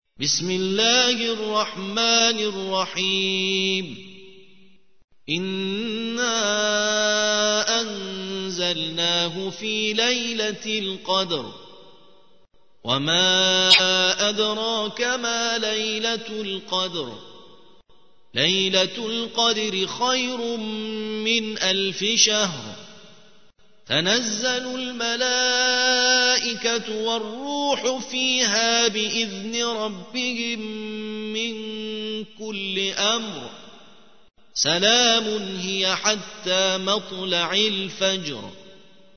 97. سورة القدر / القارئ